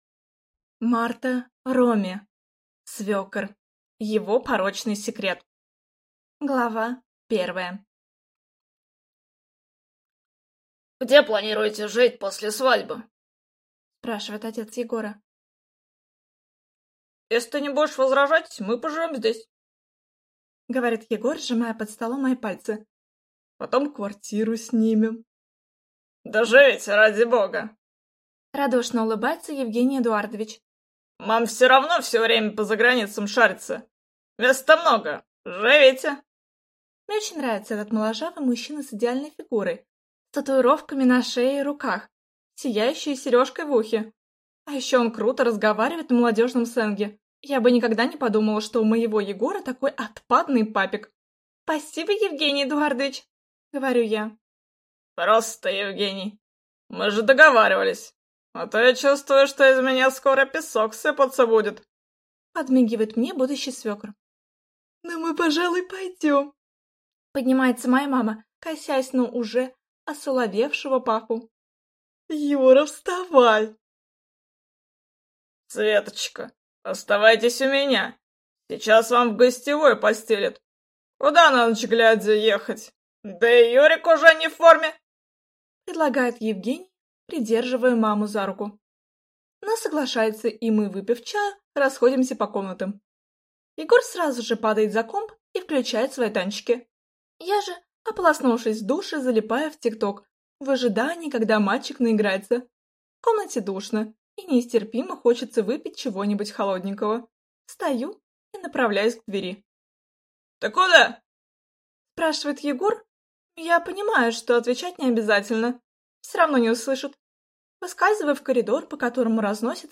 Аудиокнига Свёкор. Его порочный секрет | Библиотека аудиокниг